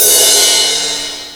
RIDE1     -L.wav